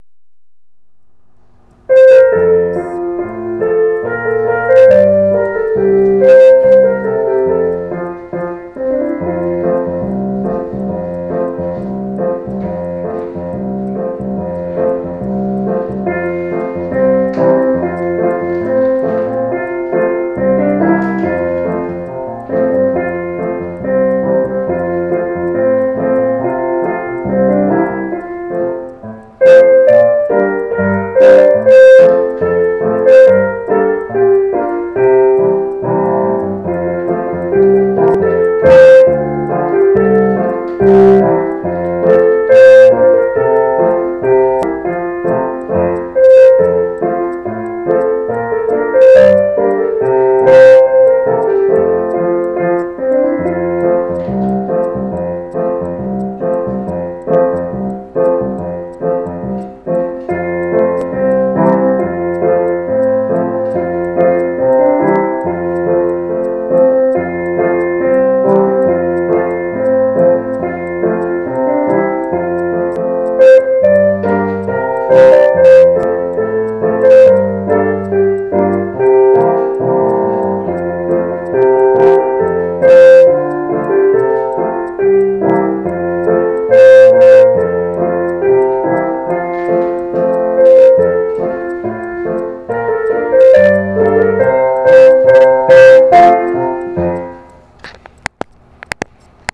Reflections of a Lad at Sea (slow tempo, keyboard only)